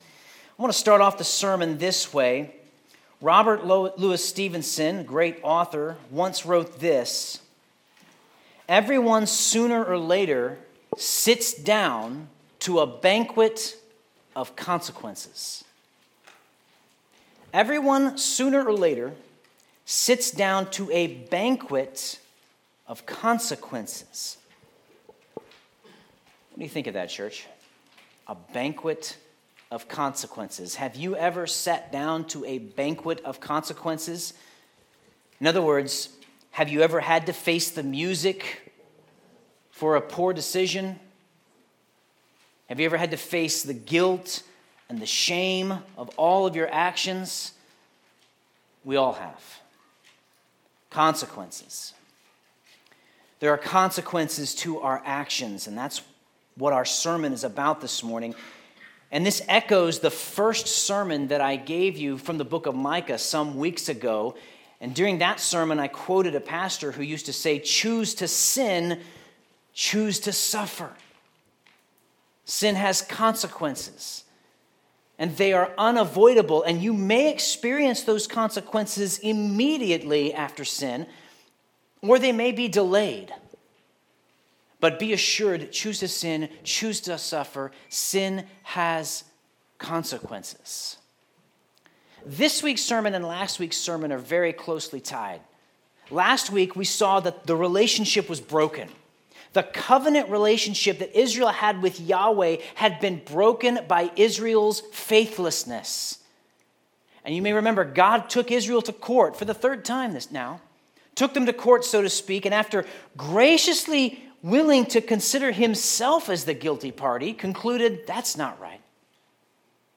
Sermon Notes Breaking faith with God results in consequences.